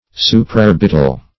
Supraorbital \Su`pra*or"bit*al\, Supraorbitar